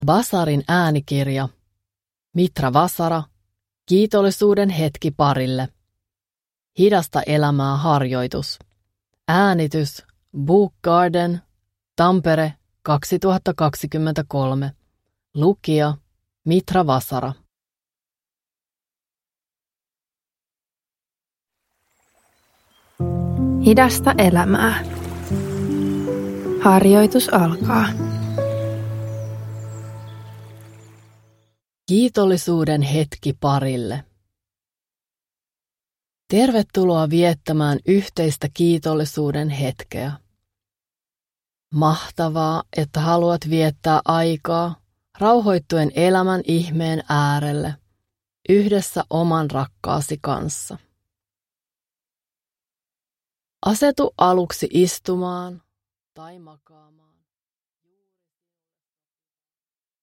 Parihetki-harjoitus